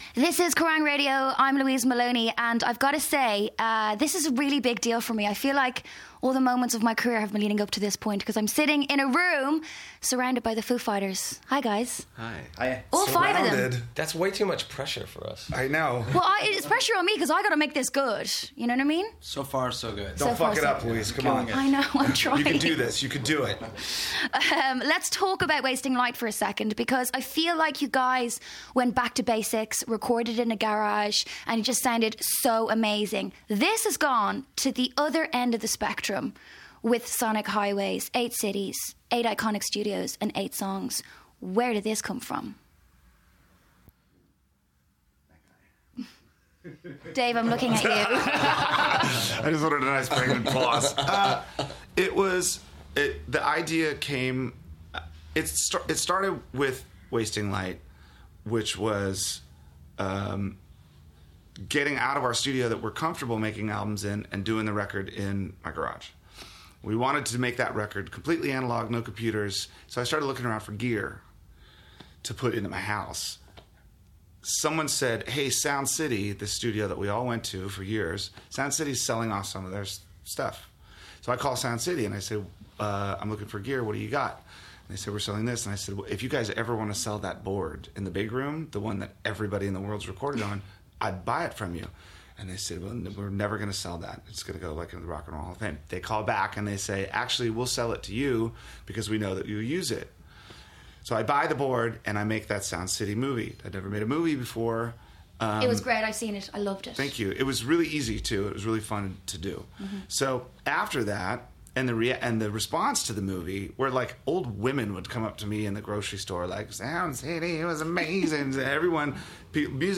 Contains some bad language.